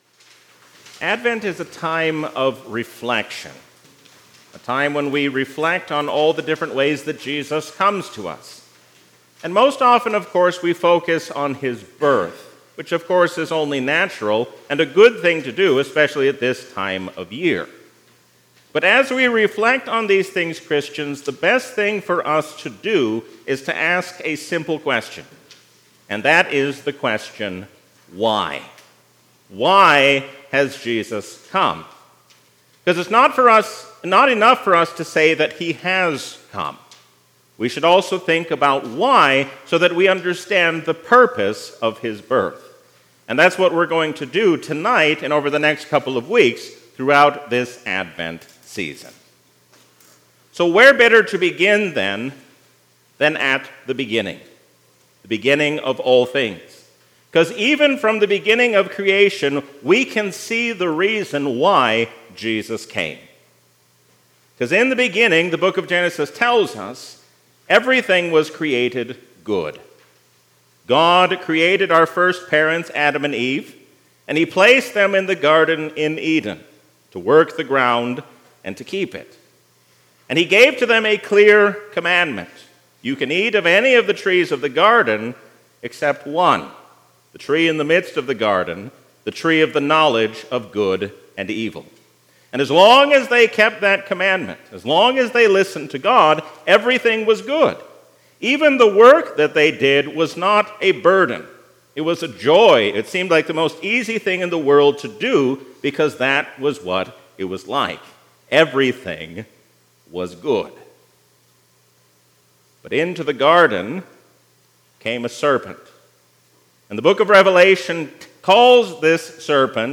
A sermon from the season "Advent 2023." We can trust all the promises of God, because we have God's favor in His Son.